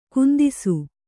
♪ kundisu